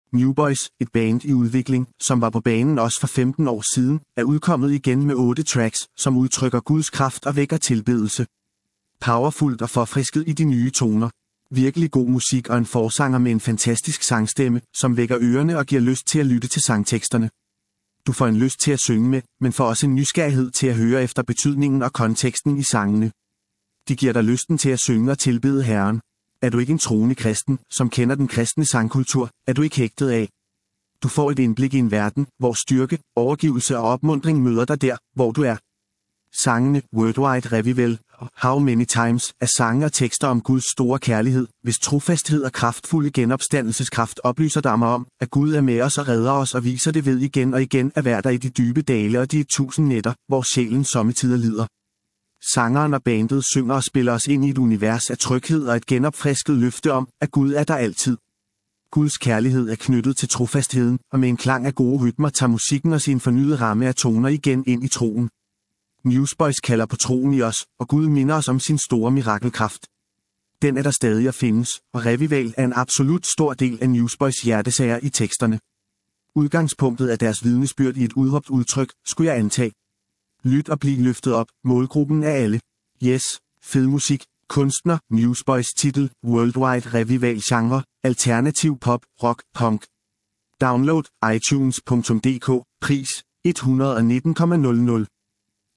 Genre: Alternativ Pop/rock/punk